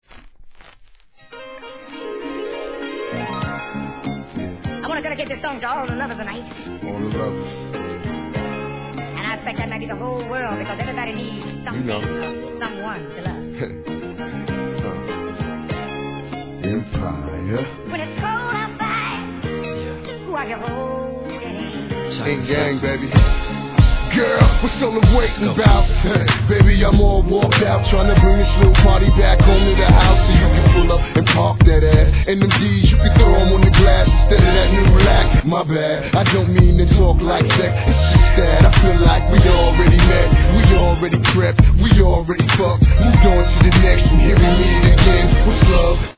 HIP HOP/R&B
ハーブの音色が曲全体に広がる様な好ミッドナンバーに優しく温かみのあるメロウヴォイスがとろける要チェックR&B!!!